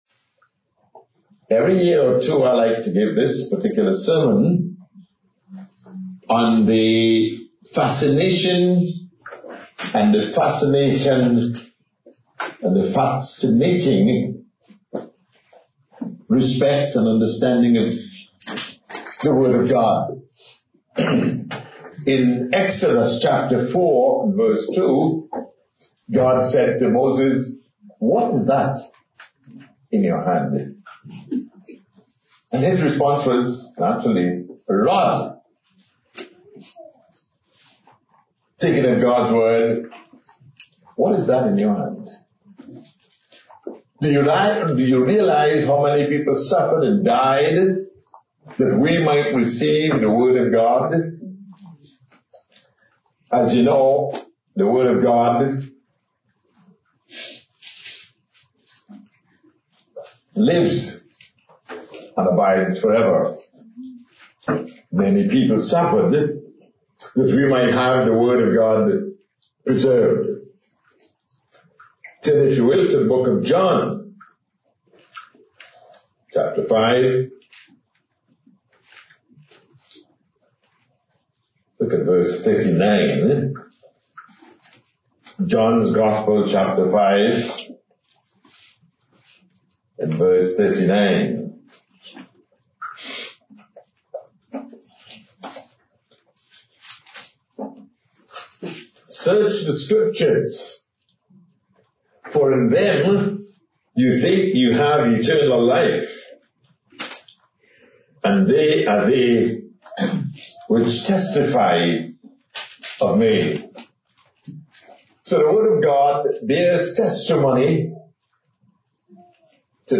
His captivating voice complemented his honest and loving sermons which always pointed people towards a relationship with God.